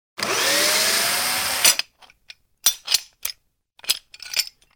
unbolt.wav